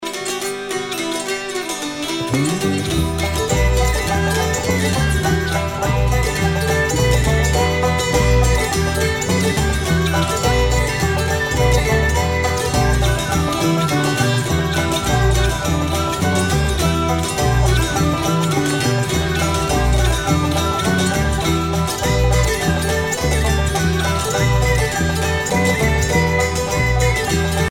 danse : an dro
Pièce musicale éditée